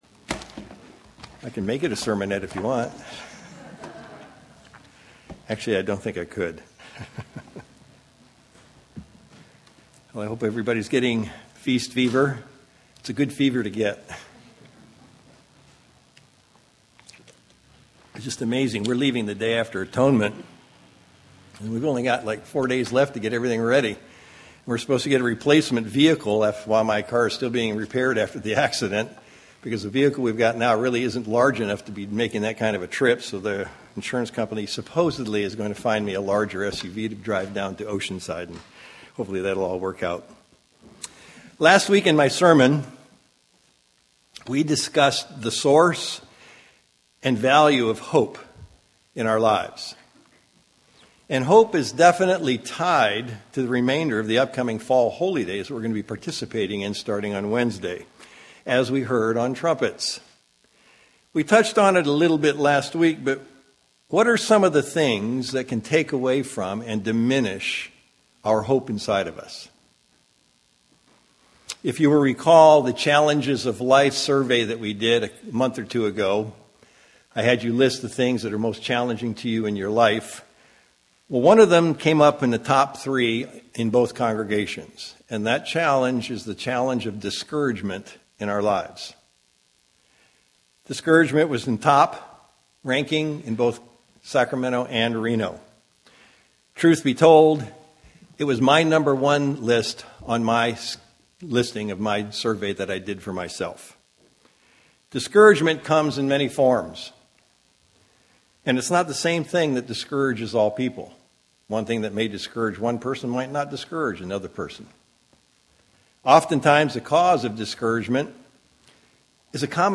Sermons
Given in Sacramento, CA